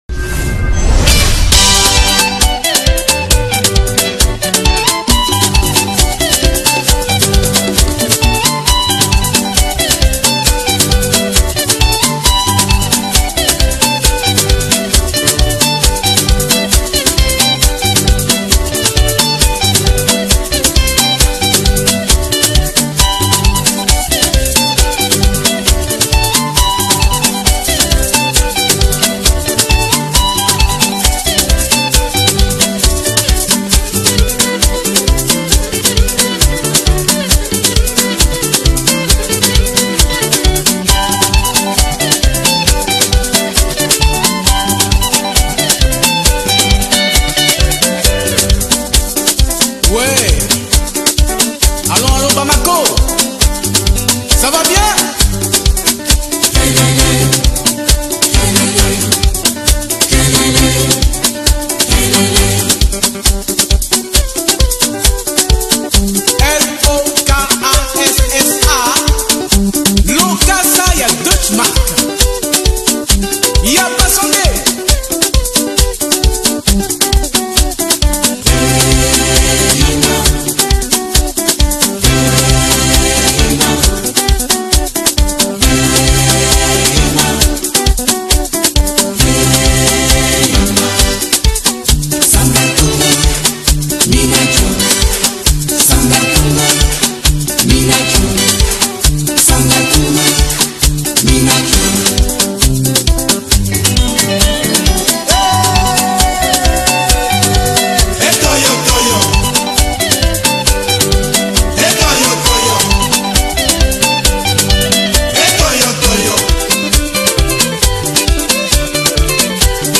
AudioCongoZilipendwa